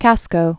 (kăskō)